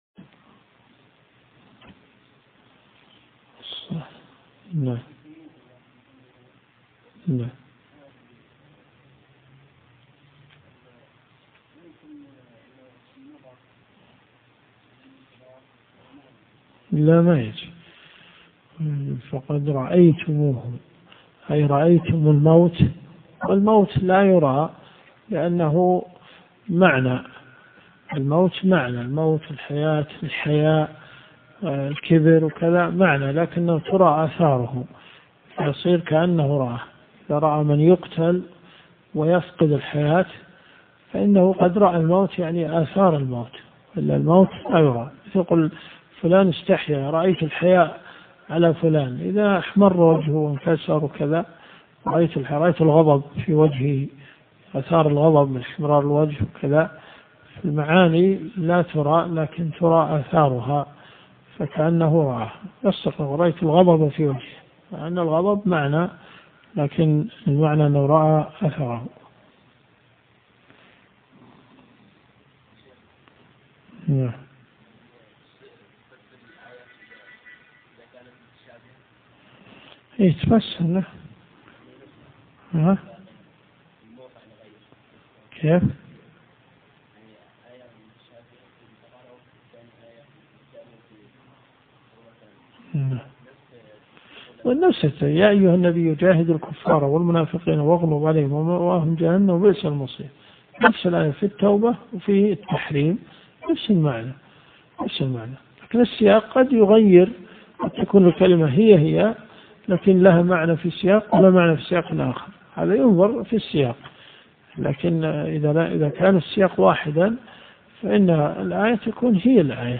دروس صوتيه ومرئية تقام في جامع الحمدان بالرياض
تفسير القران . سورة آل عمران . من آية 146 -إلى- آية 152 - الدرس بدء في الدقيقة 2 .